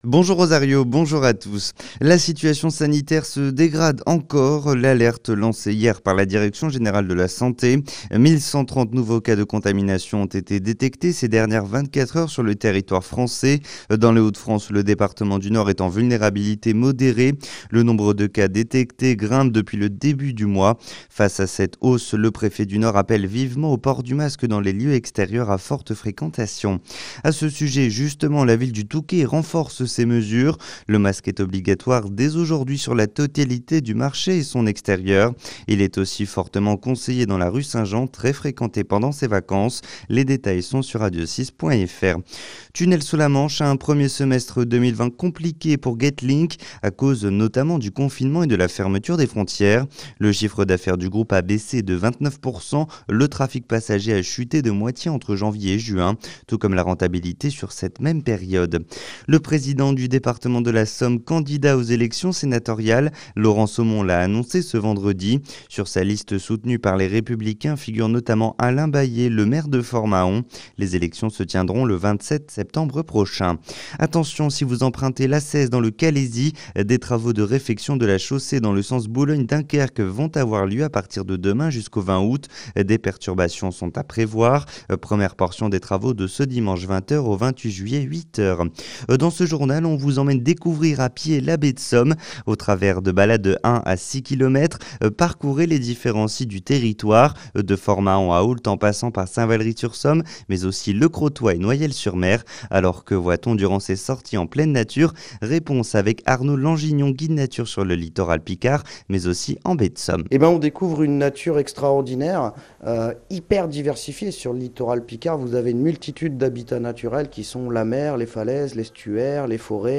Le journal Côte d'Opale et Côte Picarde du samedi 25 juillet